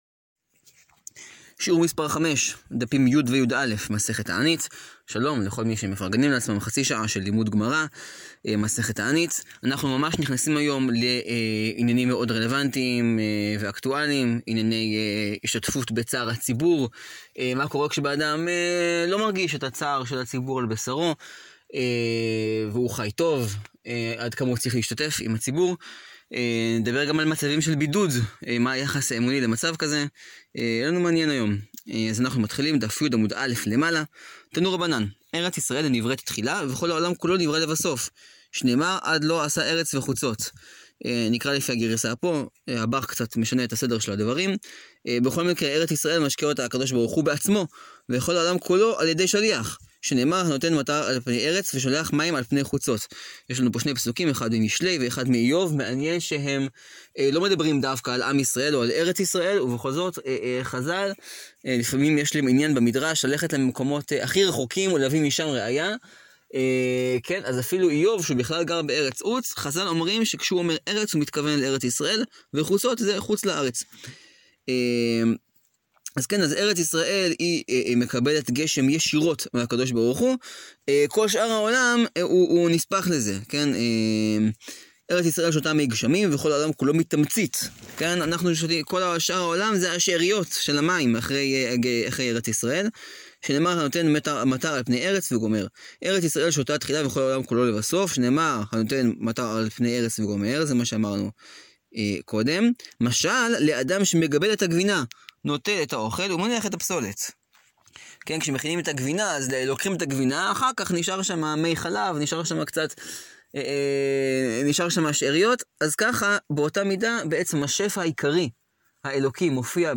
שיעור 5 להאזנה: מסכת תענית, דפים י-יא.